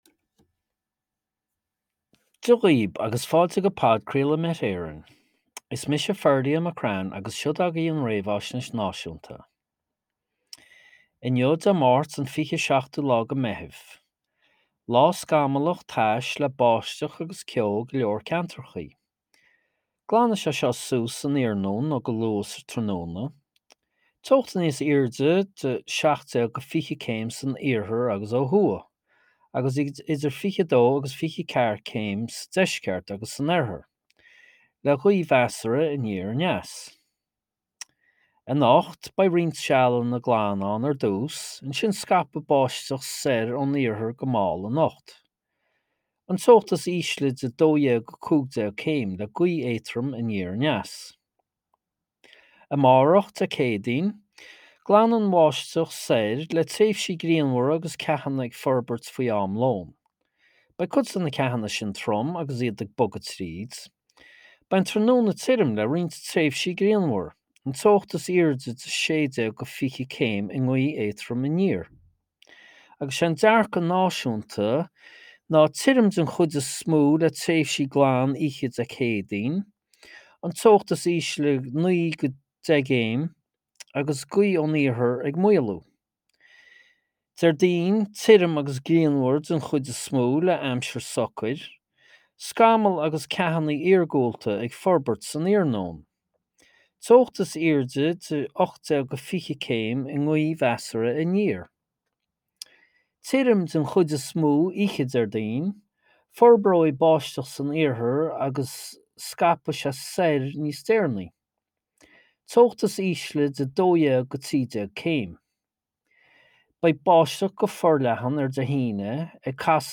Weather Forecast from Met Éireann / Podchraoladh Met Éireann